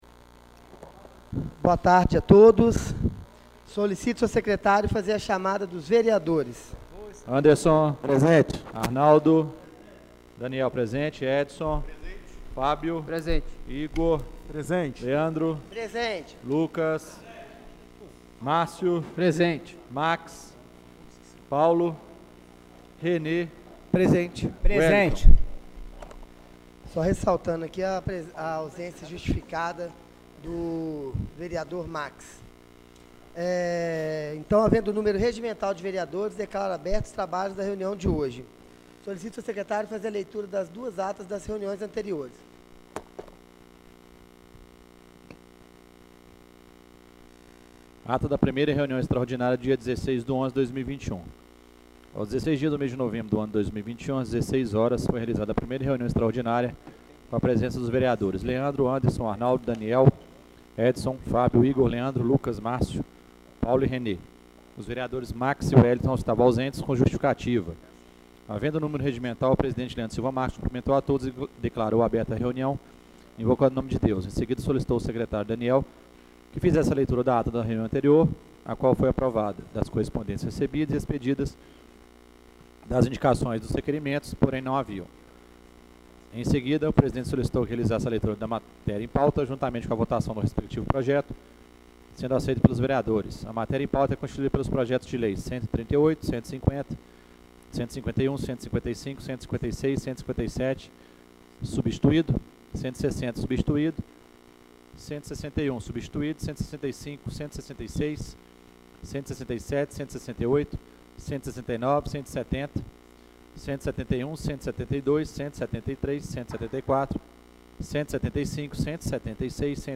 Reunião Ordinária do dia 18/11/2021